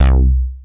RM12BASS C2.wav